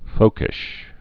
(fōkĭsh)